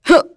Glenwys-Vox_Jump.wav